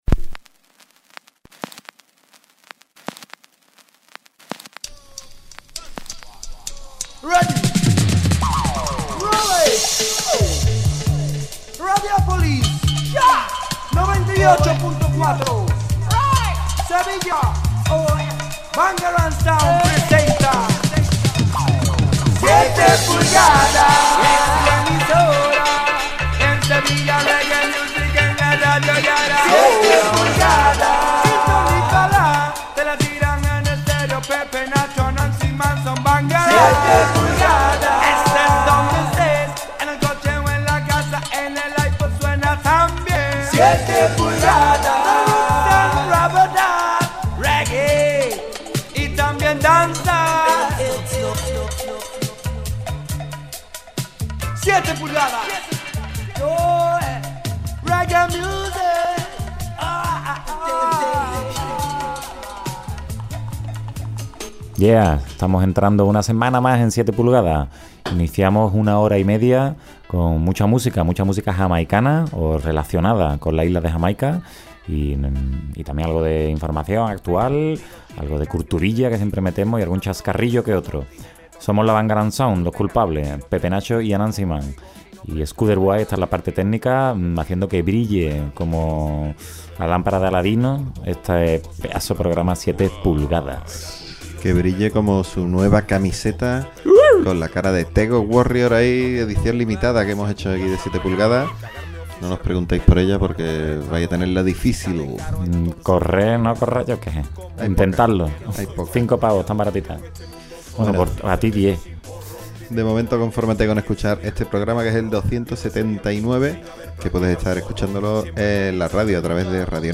Presentado y dirigido por la Bangarang Sound y grabado en la Skuderbwoy House.